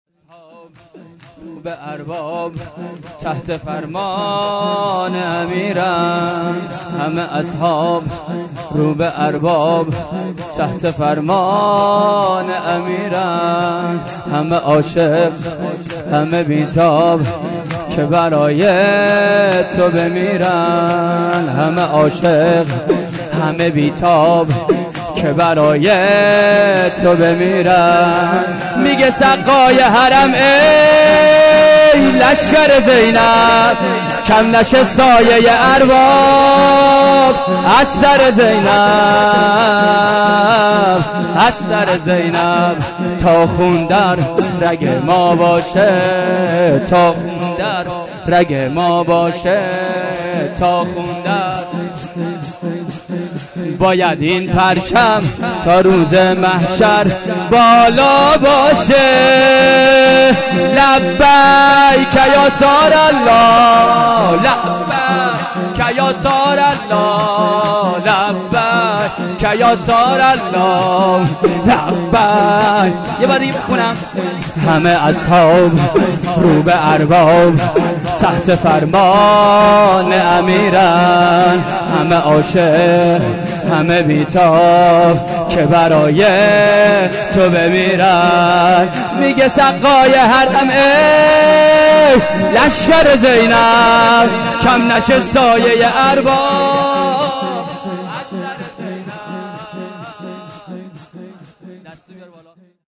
شور کربلایی
شب‌ نهم محرم الحرام ۹۷ هیات انصار المهدی (عج)